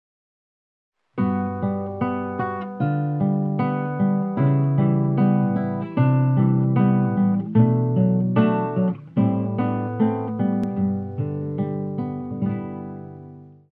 failsound.ogg